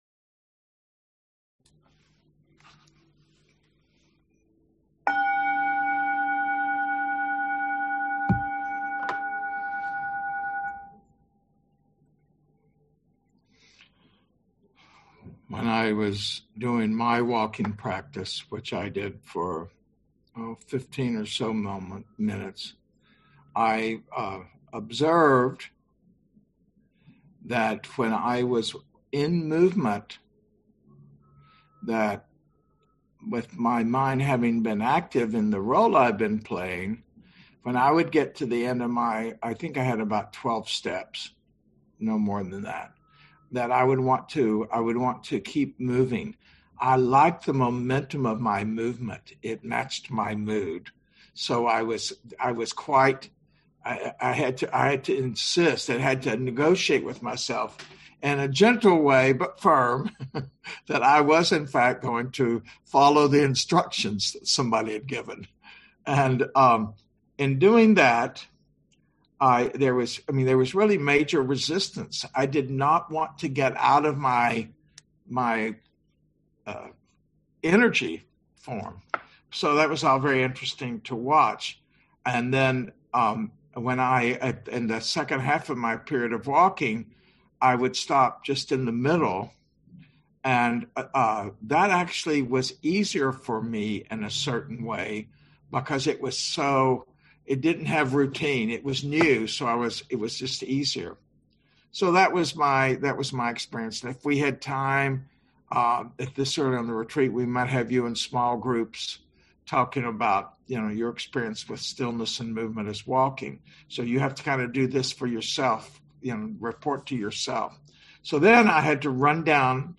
Day 1 morning guided meditation